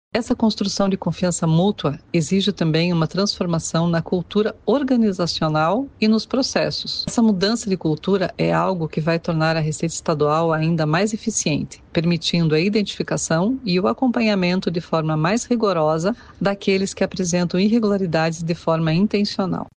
Sonora da diretora da Receita Estadual, Suzane Gambetta, sobre ações do Estado para manter os contribuintes em dia com os tributos